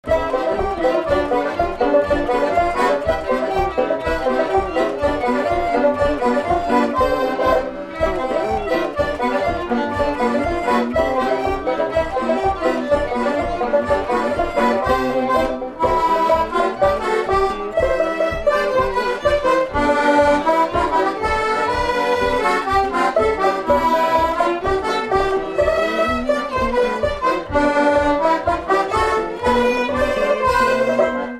Tampon (Le)
Instrumental
danse : séga
Pièce musicale inédite